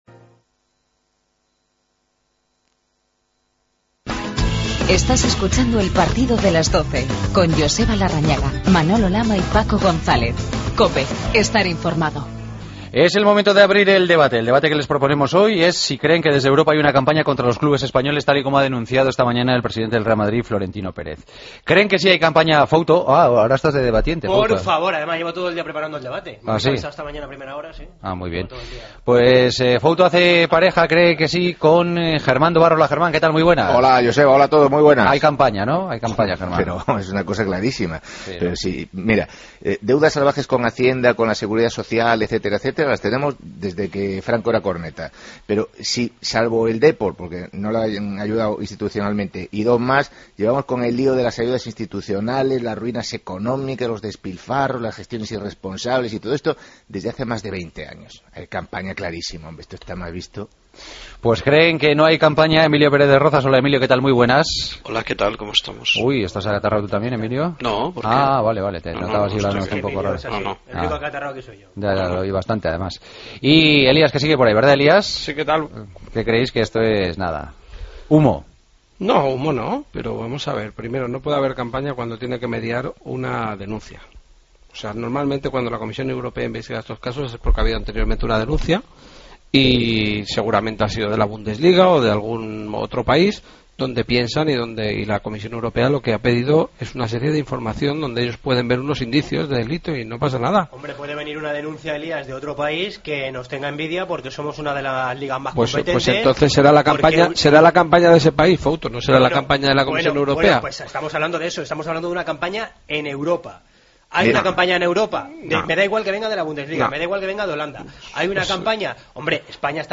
El debate de los jueves: ¿Creéis que desde Europa hay una campaña contra los clubes españoles?